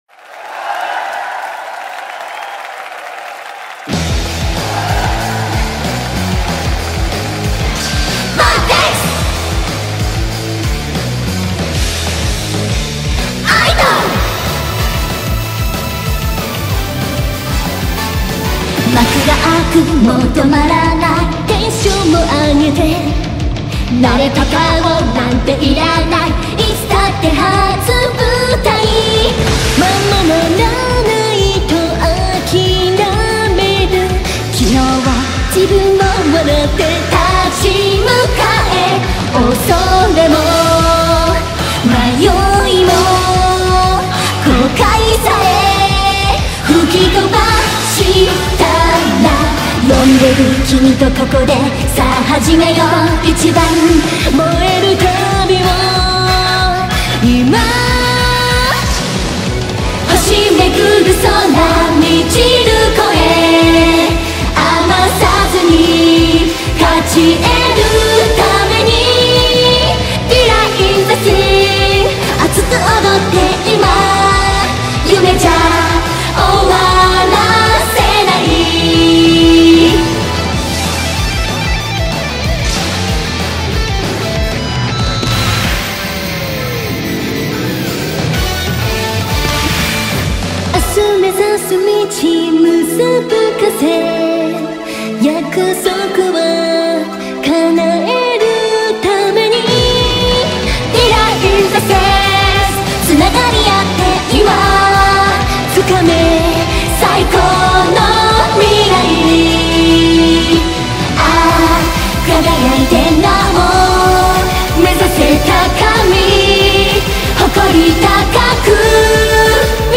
BPM188
Audio QualityCut From Video